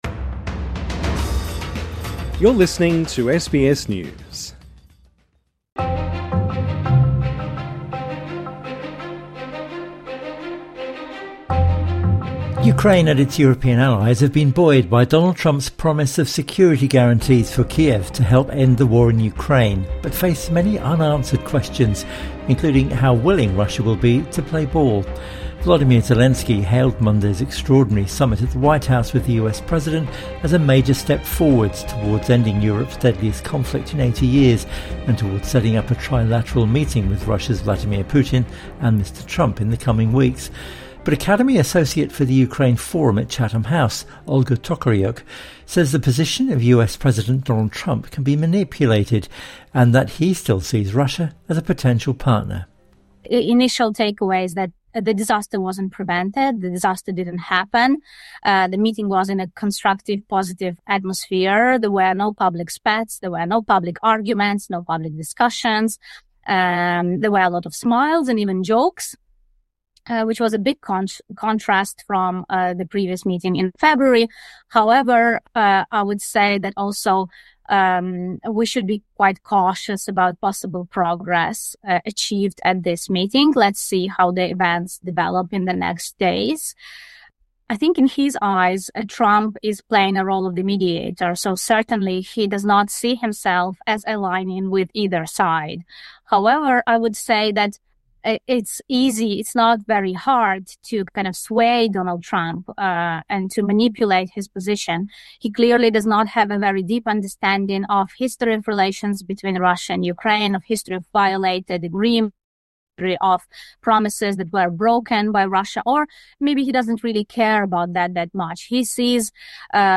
INTERVIEW: What next, following the Ukraine talks at the White House? 6:17